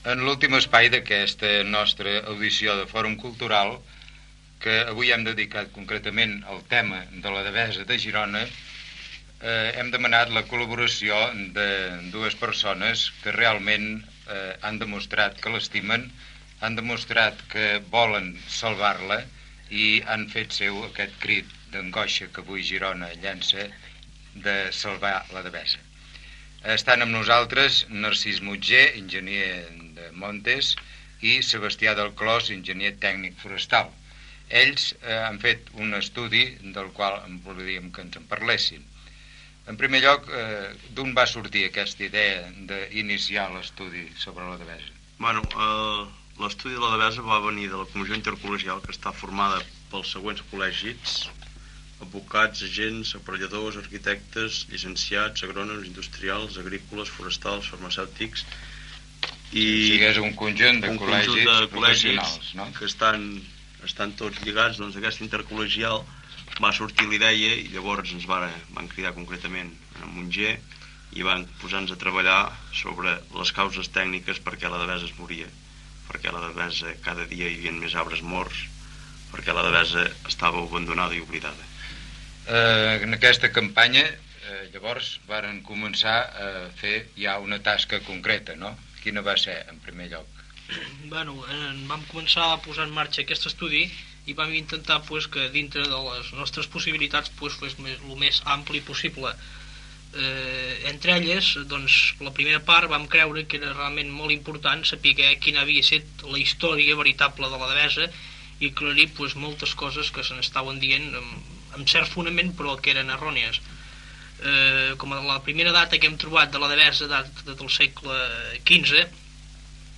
Entrevista
Fragment extret del programa "Ràdio Girona, 65 anys" emès per Ràdio Girona 2 l'any 1998